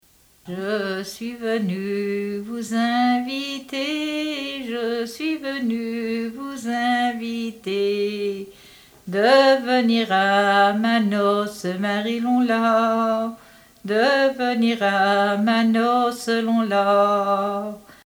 Origine : Bretagne (pays Bigouden)
chant Bigouden